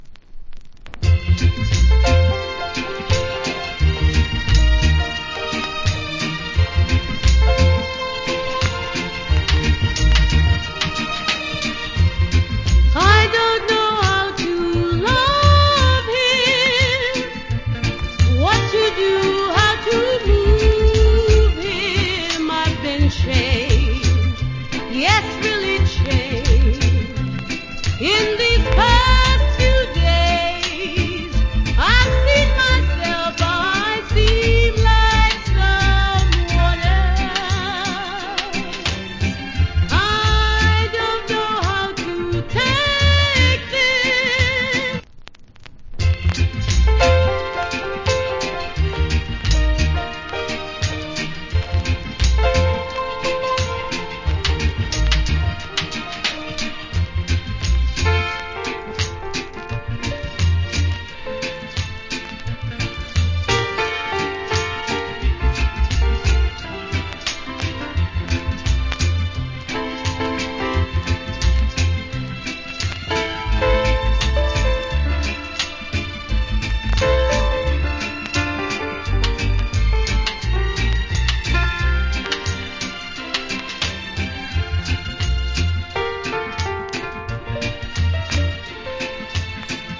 Cool Female Reggae Vocal.